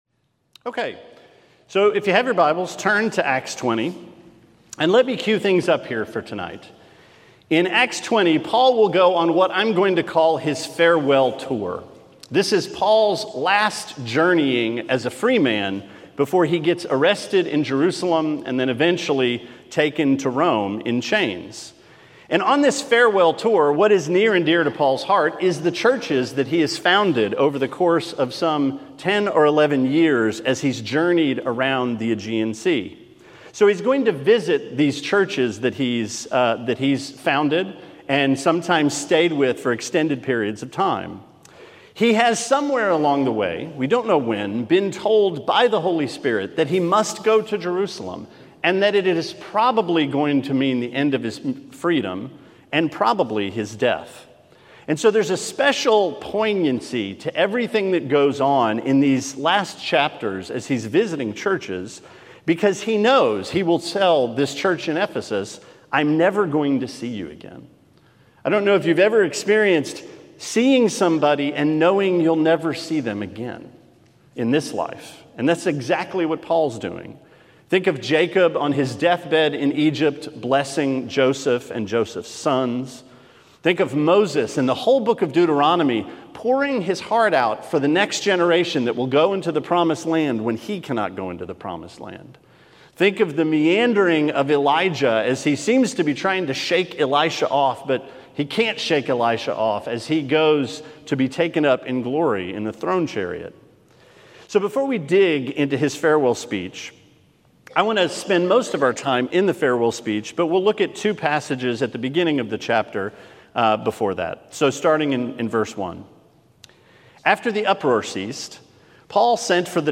Sermon 4/10: Acts 20: Humility, Tears, and Trials – Trinity Christian Fellowship